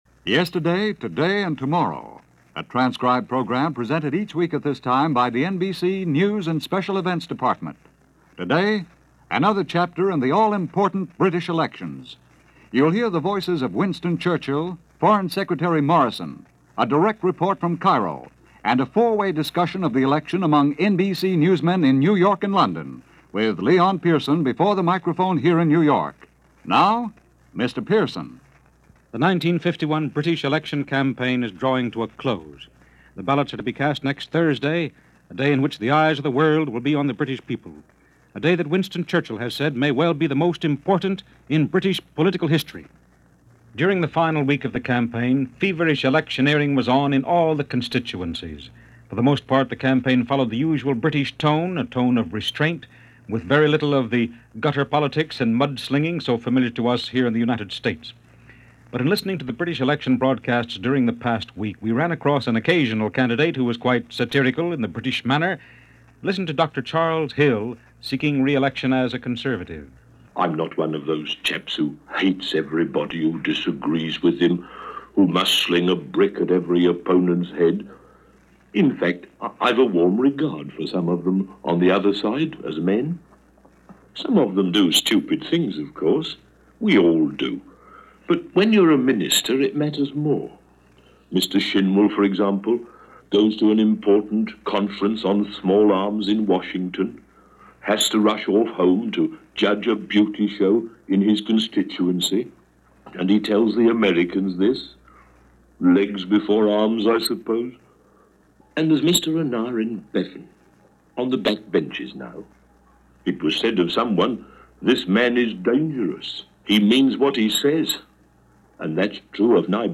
Documentary on the 1951 British Elections: Past Daily Reference Room.